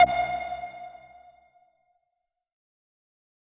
K-1 Sonar Stab.wav